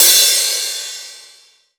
Crashes & Cymbals